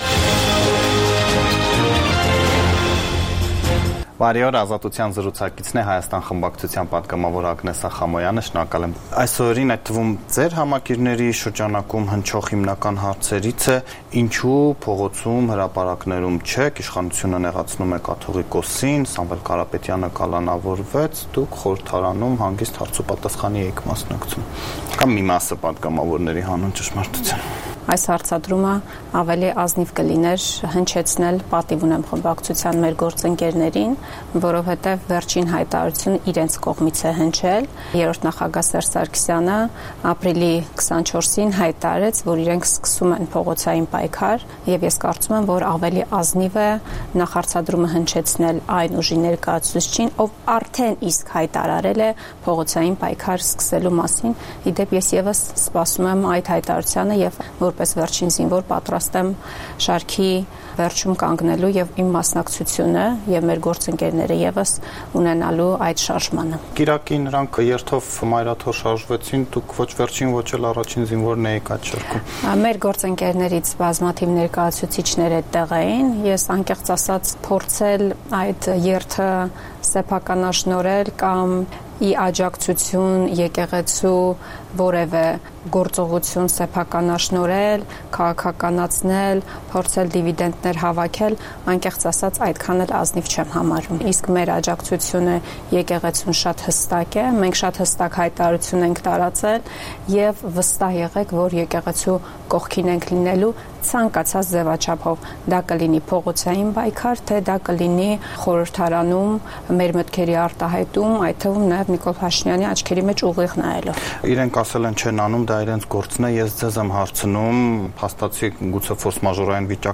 Տեղական եւ միջազգային լուրեր, ռեպորտաժներ, հարցազրույցներ, տեղեկատվություն օրվա սպասվող իրադարձությունների մասին, մամուլի համառոտ տեսություն: